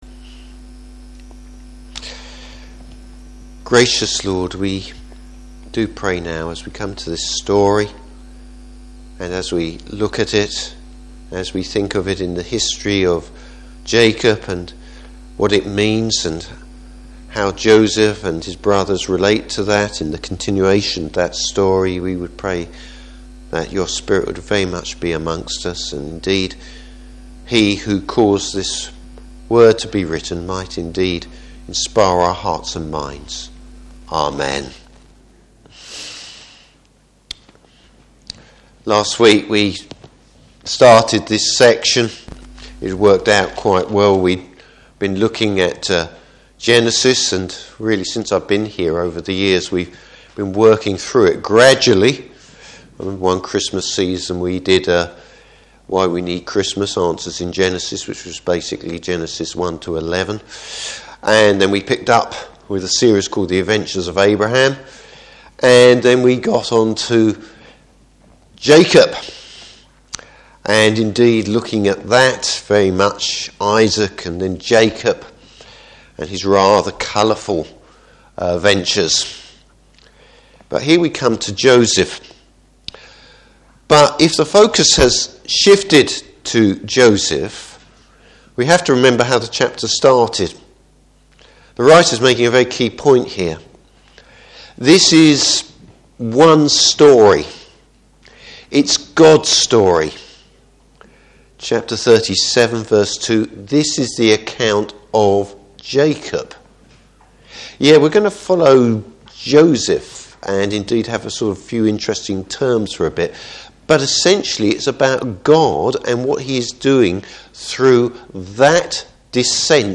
Service Type: Evening Service Tragedy , or God at work?